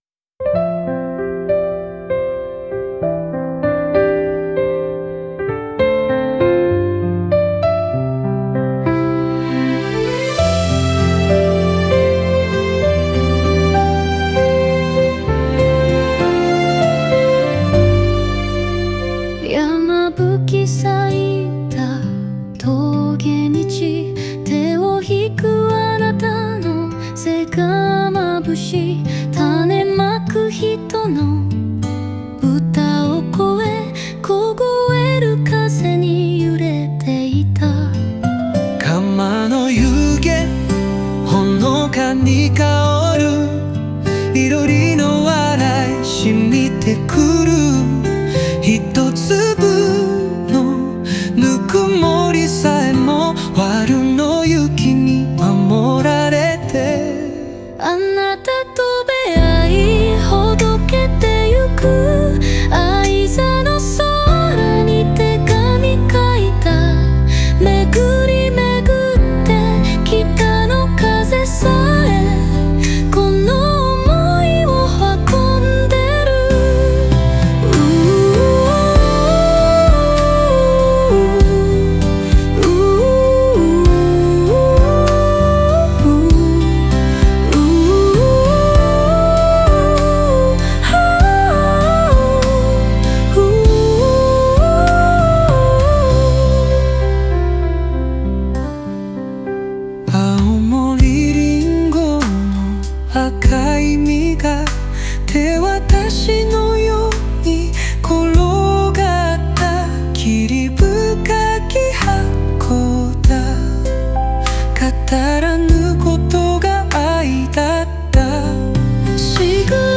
この歌は、東北の四季とともに移ろう人生の出逢いと別れ、そして「見えない絆」を描いたバラードです。